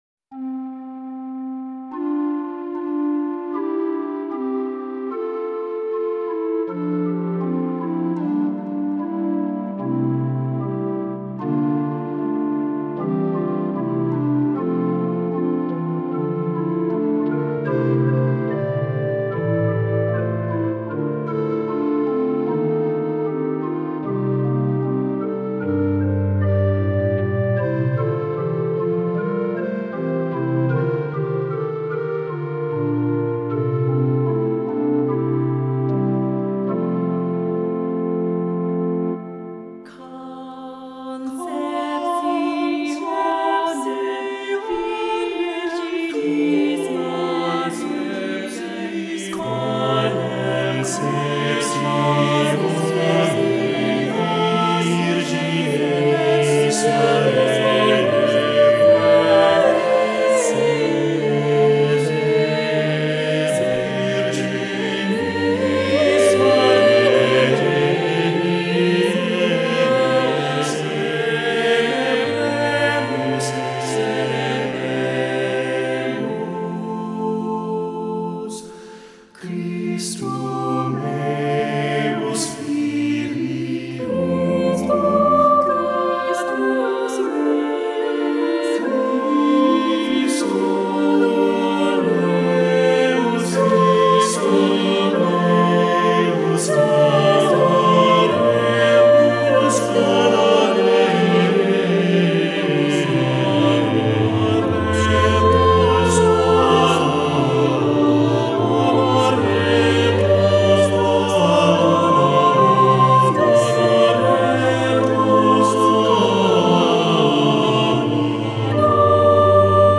Voicing: "SAATB"